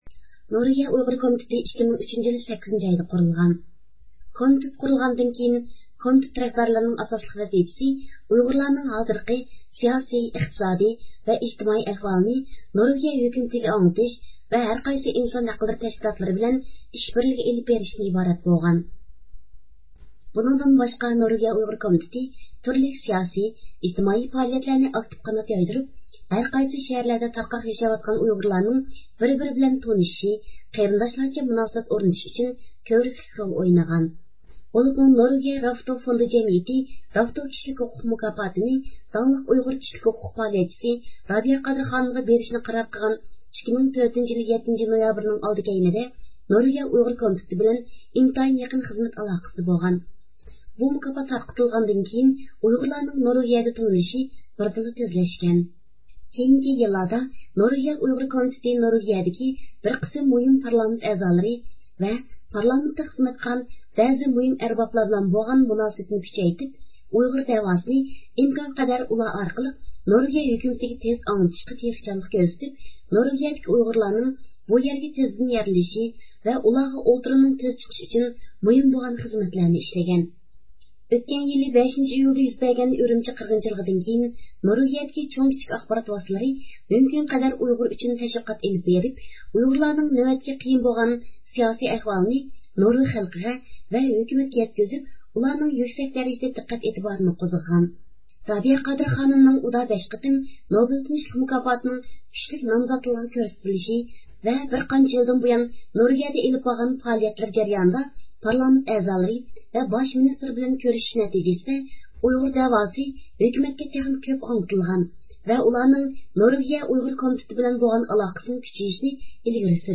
ئىختىيارىي مۇخبىرىمىز
سۆھبەت ئۆتكۈزۈلدى.